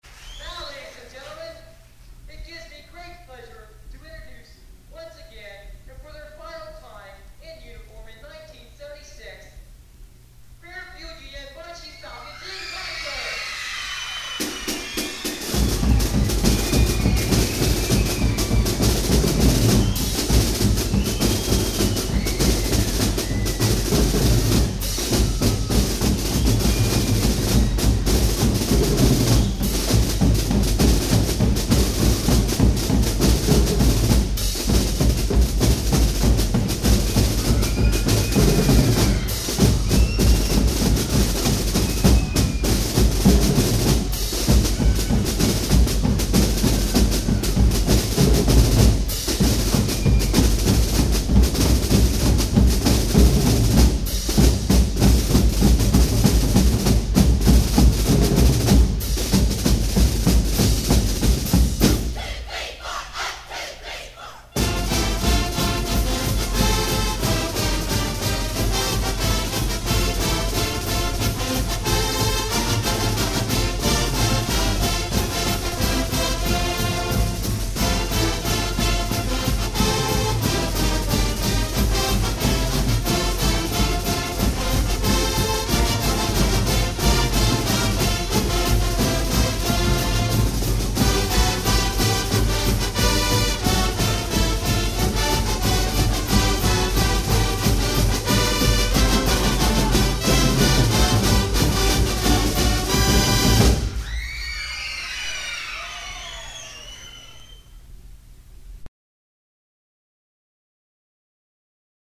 Opening Drum Cadence and Fight Song